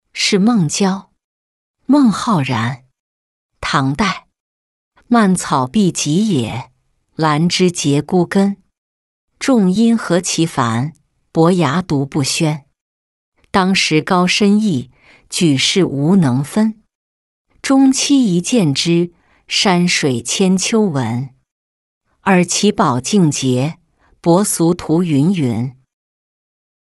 示孟郊-音频朗读